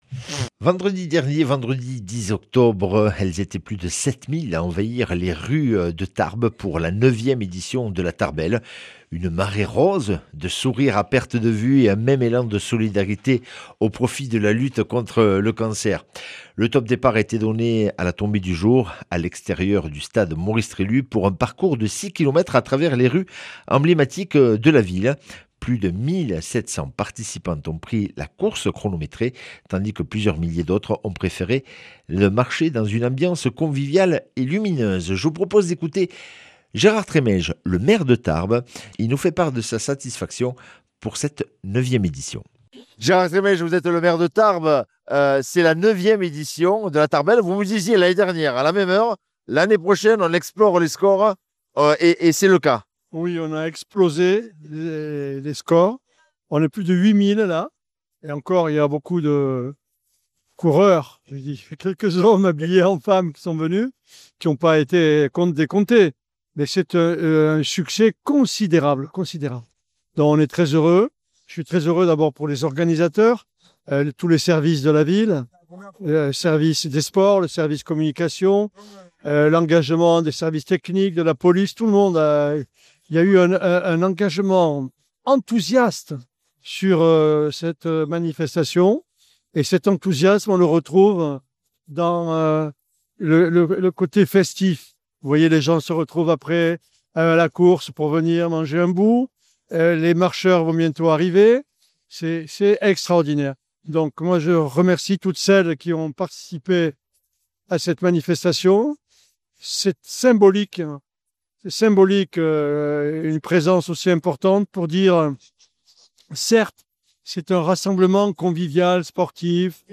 Interview et reportage